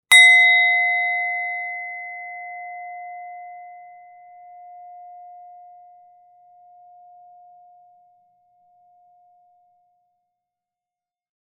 Crisp Ding Sound Effect
Clean ting sound effect delivers a sharp, clear metallic ping. This single-tone hit metal sound works perfectly for transitions, notifications, and alerts.
Crisp-ding-sound-effect.mp3